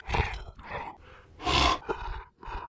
dog_roar1.ogg